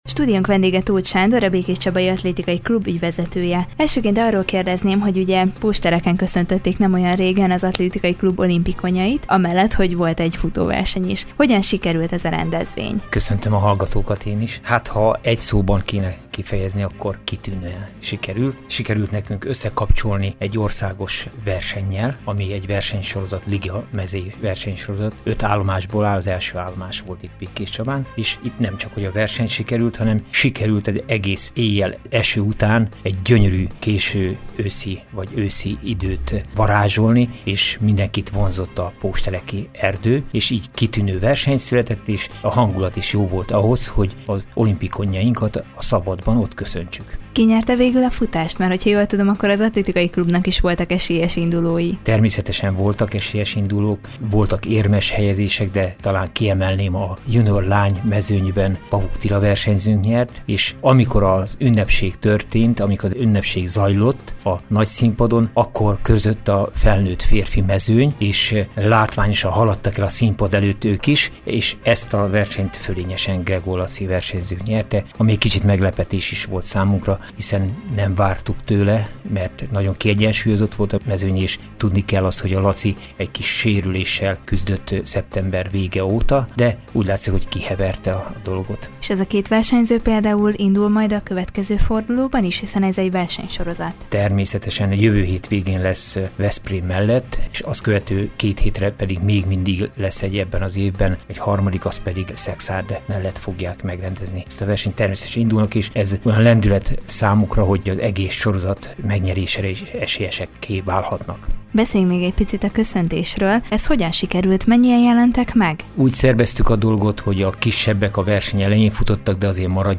Vele beszélgetett tudósítónk a közelmúltban megrendezésre került a országos futóversenyről valamint a csabai olimpikonok köszöntéséről.